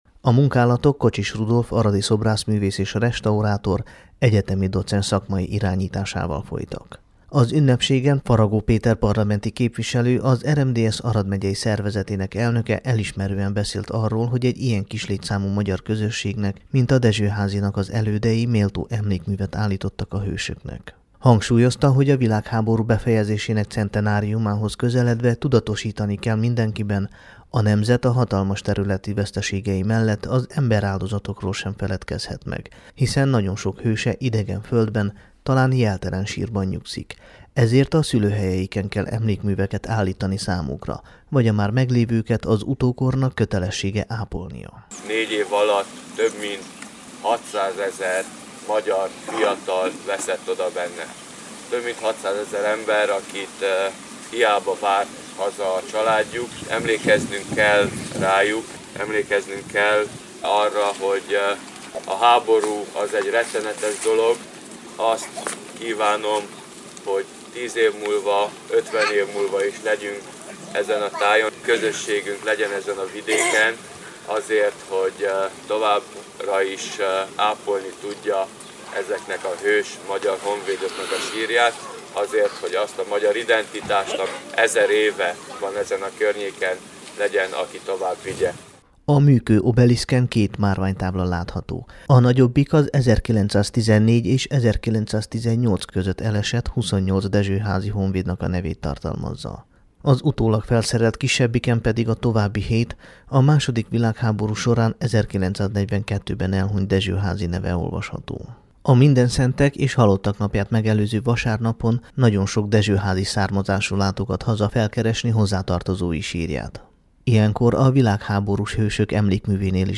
Dezsohazi_vilaghaborus_emlekmu_avatasa.mp3